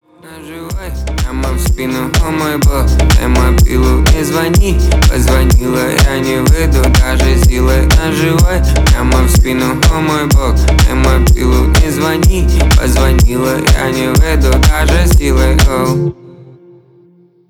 • Качество: 320, Stereo
ритмичные
Rap